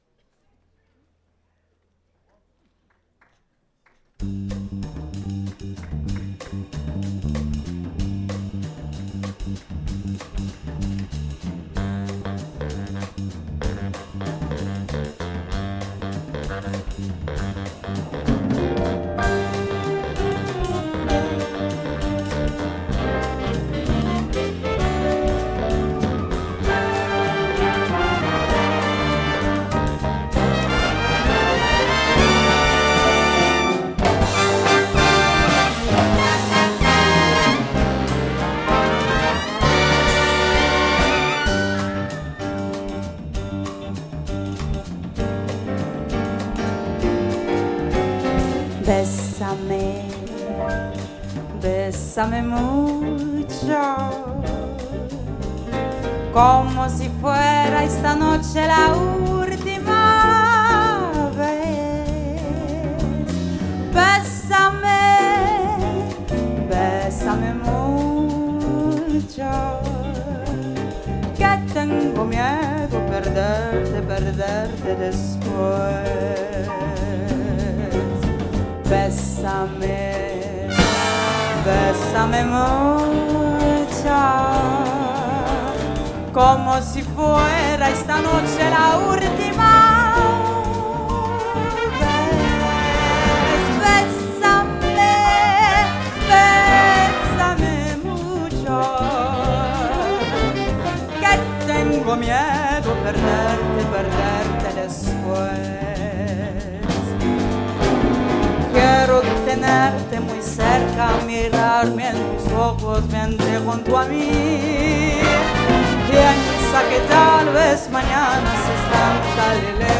sur la scène du festival en 2024
Ce boléro en do mineur est devenu la chanson en espagnol la plus reprise du XXe siècle.
Saxophone
Trombone
Chant